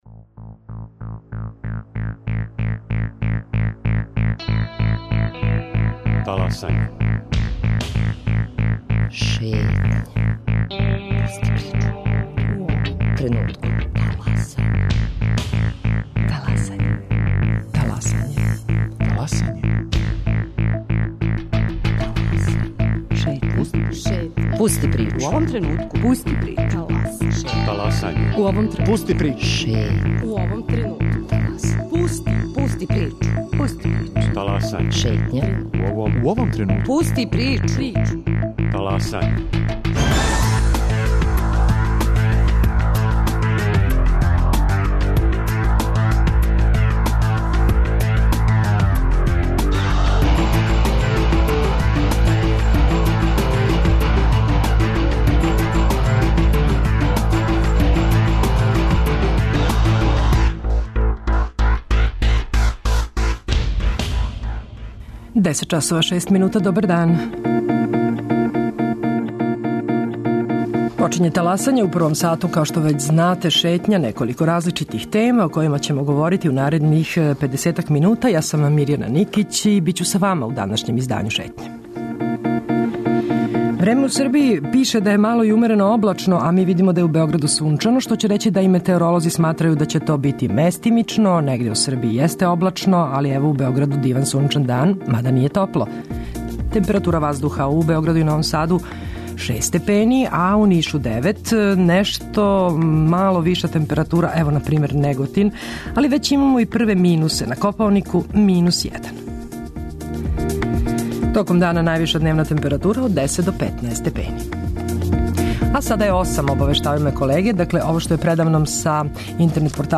Наши гости су и ученици Математичке гимназије, који су пре неколико дана освојили три бронзане медаље на Светској олимпијади из информатике у Букурешту.